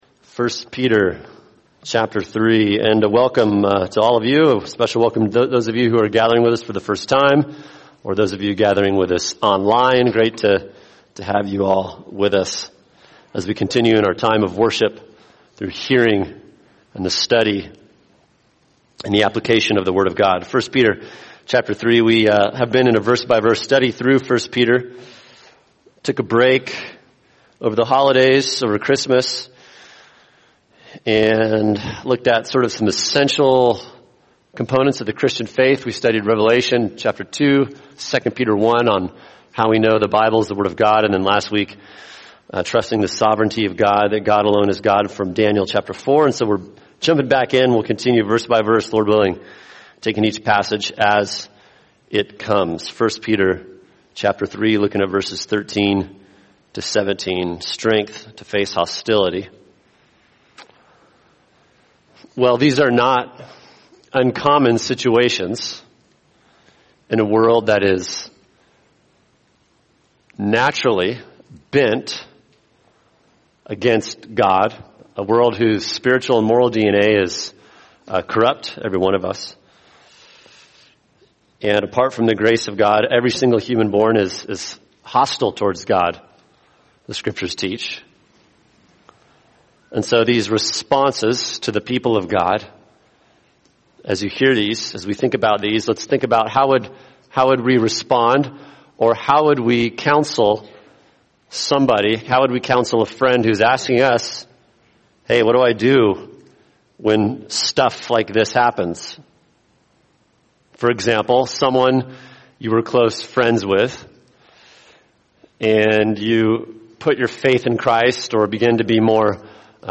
[sermon] 1 Peter 3:13-17 Strength to Face Hostility | Cornerstone Church - Jackson Hole